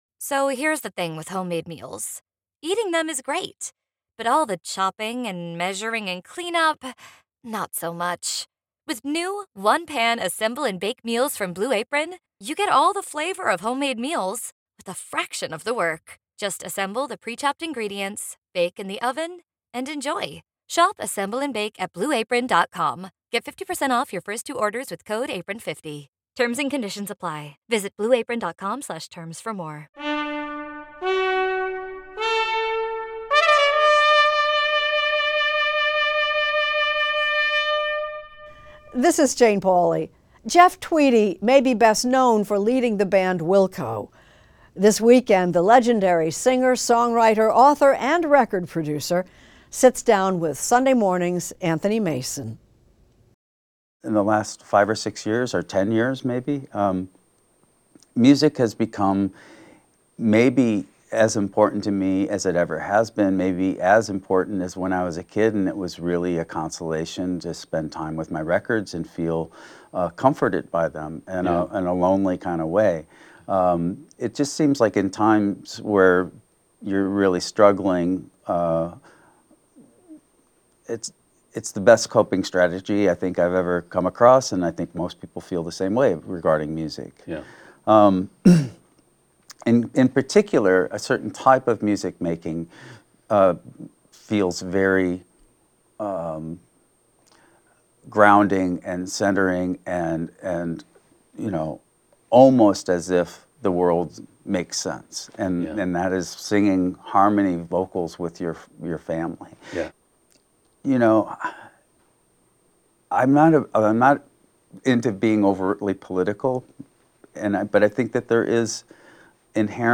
Extended Interview: Jeff Tweedy
Jeff Tweedy, front man of the rock group Wilco, talks with correspondent Anthony Mason about his solo project, a triple album called "Twilight Override."